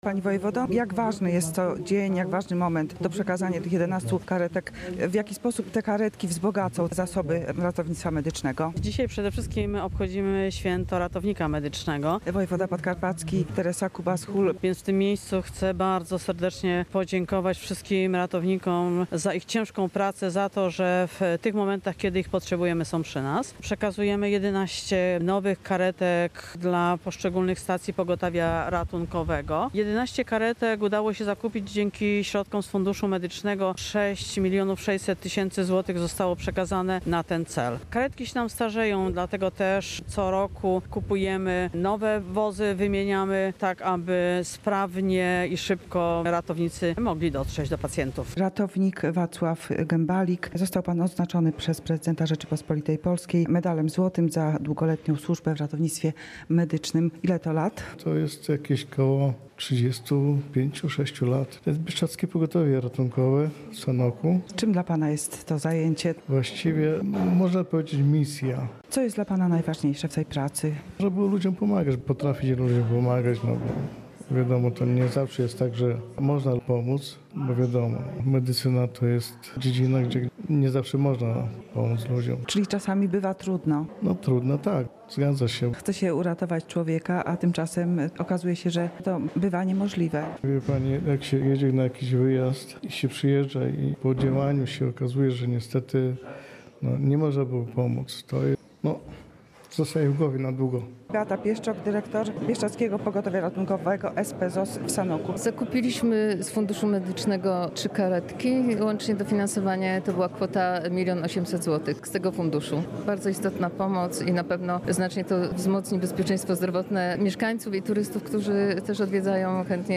Wręczono odznaczenia, przekazano nowe karetki – tak przebiegły wojewódzkie obchody Dnia Ratownictwa Medycznego na Podkarpaciu. Zorganizowano je w Borowej niedaleko Mielca.